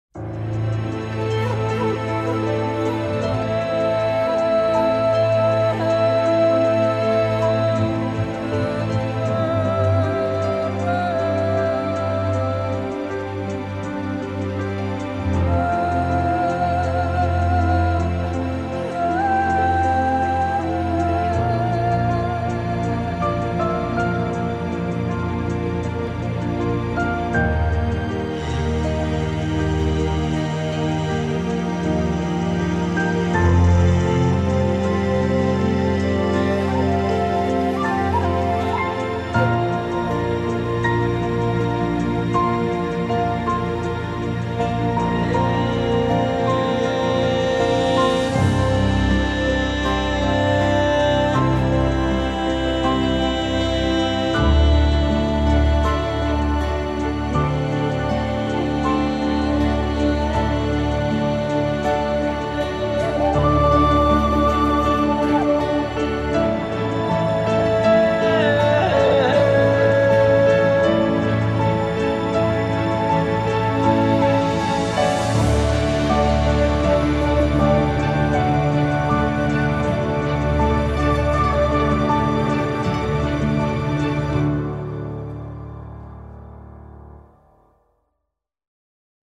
epique - piano - profondeurs - voix - ciel